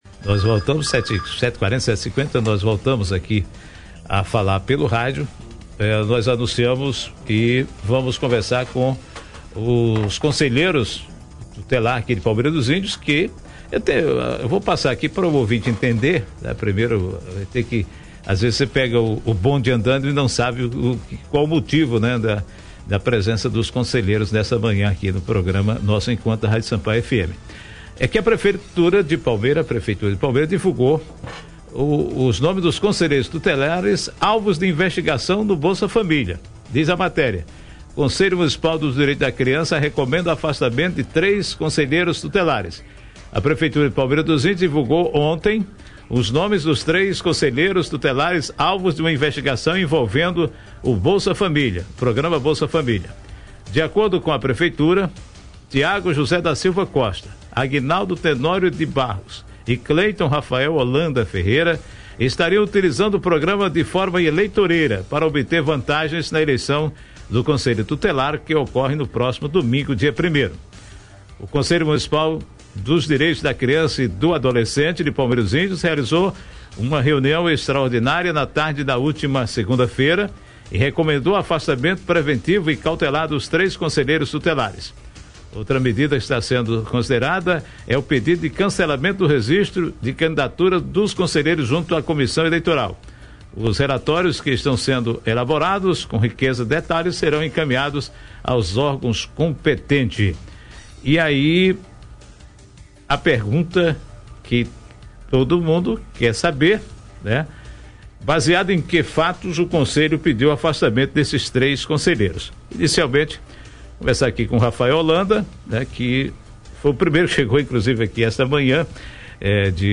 [Áudio] Conselheiros tutelares citados em envolvimento ilícito no Bolsa Família concedem entrevista à Rádio Sampaio - RÁDIO SAMPAIO